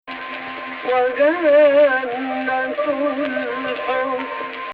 Type: "Husseini" 865
What I'm referring to here as "Husseini" is a strong emphasis on the natural 6th scale degree descending to the 5th scale degree.